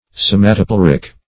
Search Result for " somatopleuric" : The Collaborative International Dictionary of English v.0.48: Somatopleuric \So`ma*to*pleu"ric\, a. (Anat.) Of or pertaining to the somatopleure.
somatopleuric.mp3